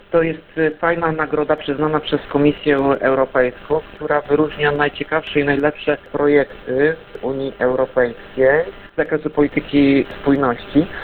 O konkursie mówi Tomasz Andrukiewicz, prezydent Ełku.